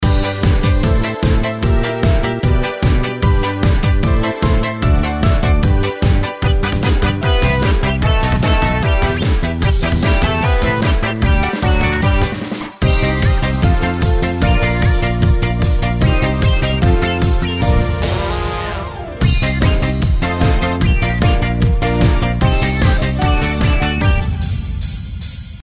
On Green Dolphin Street (Jazz Jam)